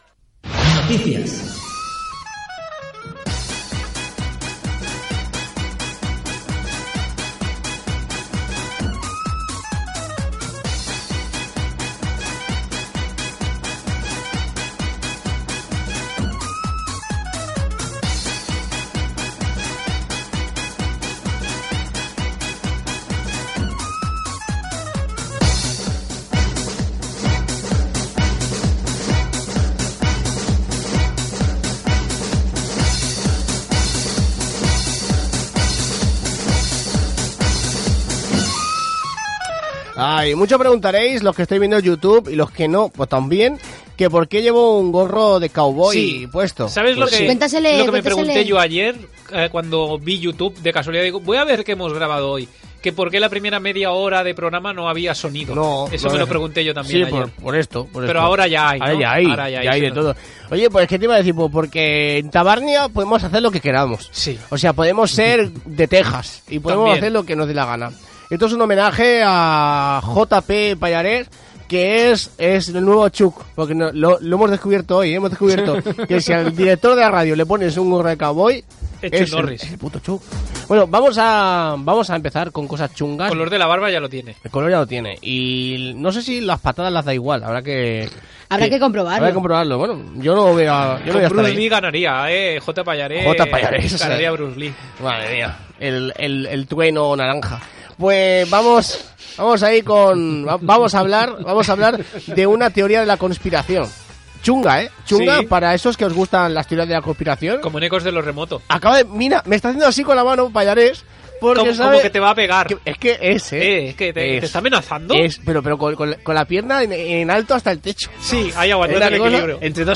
Programa fet als estudis de l'emissora municipal Ràdio Sant Boi.